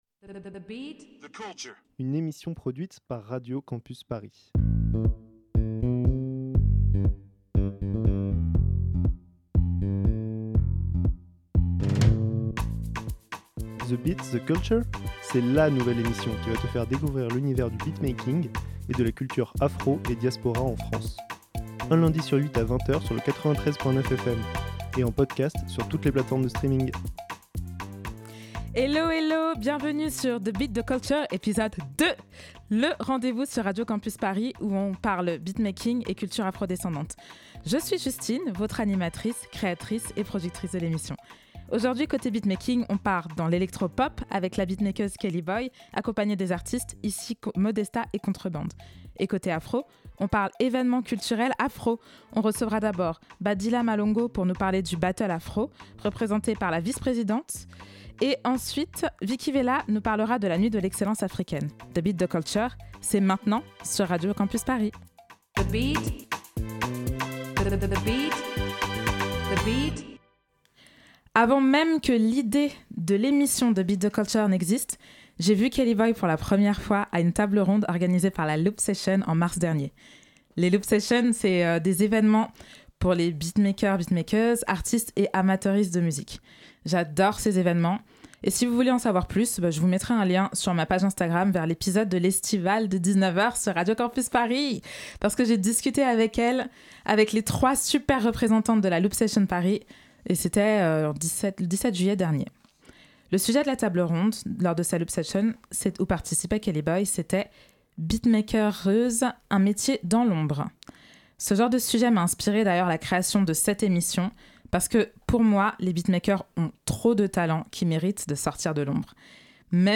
Ce soir, on a le plaisir de recevoir les représentantes de deux événements culturels incontournables.